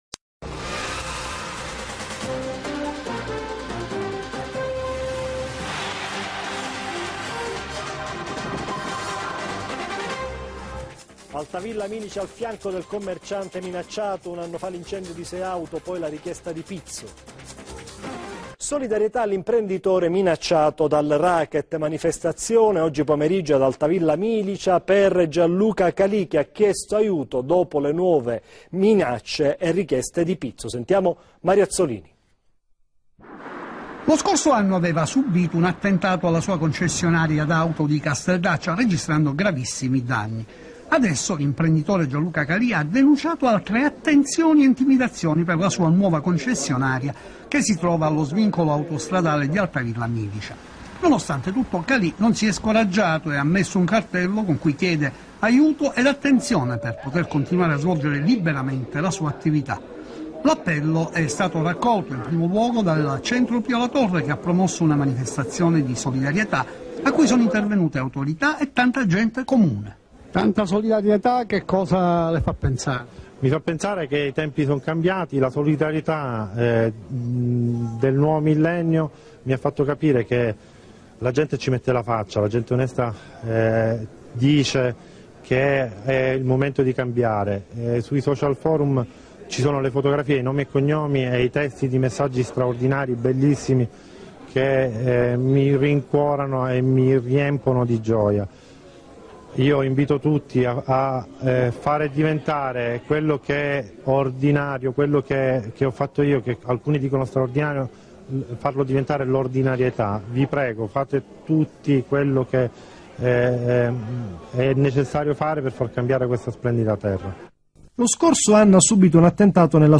ore 17 presso il Centro Esposizione Calicar (via Gelsi Neri, svincolo autostradale di Altavilla Milicia - Pa)